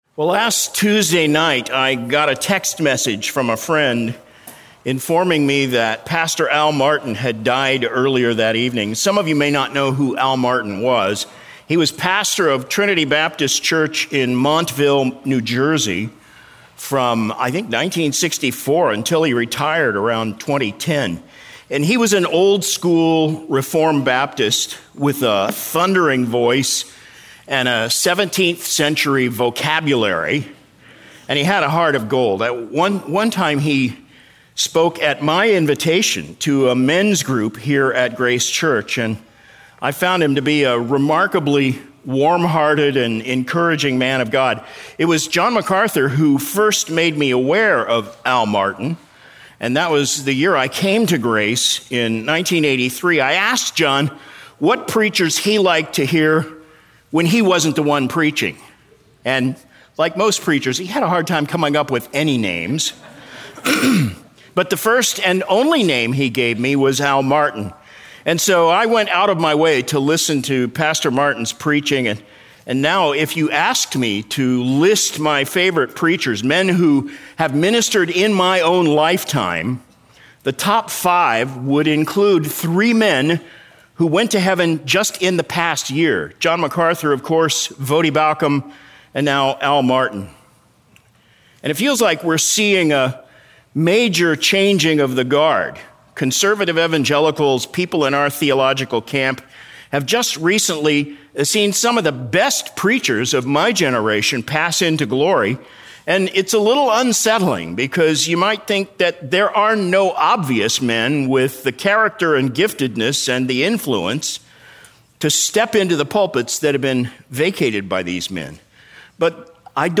April 12, 2026 - Sermon